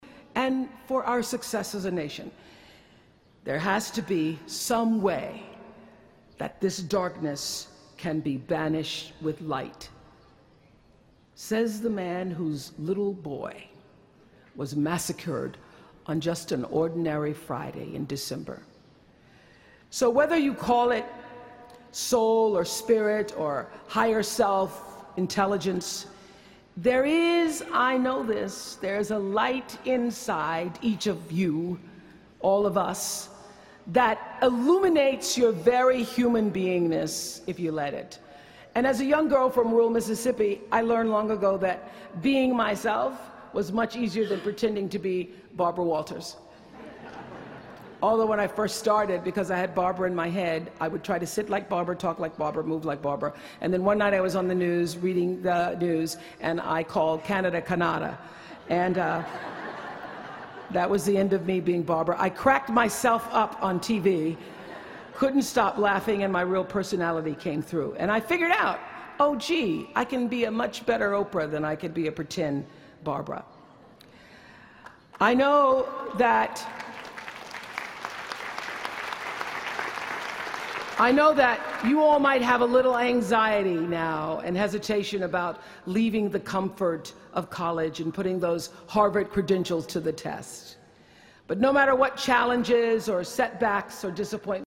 公众人物毕业演讲第366期:奥普拉2013在哈佛大学(15) 听力文件下载—在线英语听力室